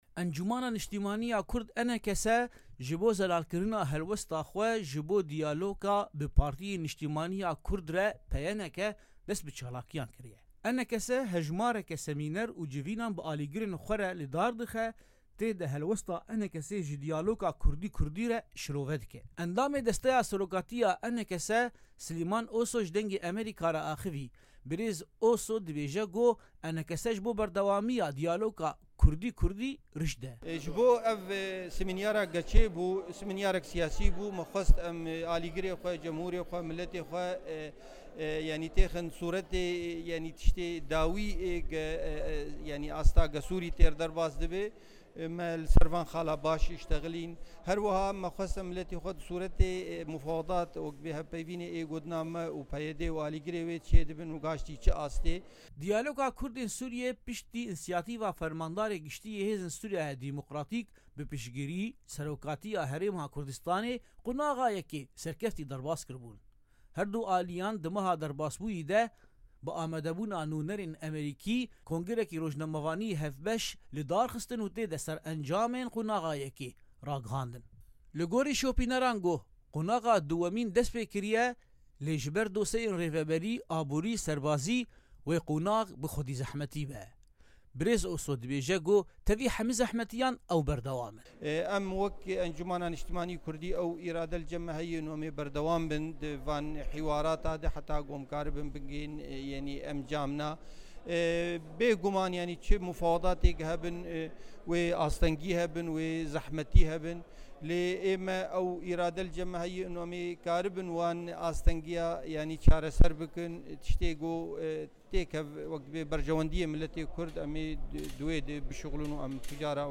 لە وتووێژێکیدا لەگەڵ دەنگی ئەمەریکا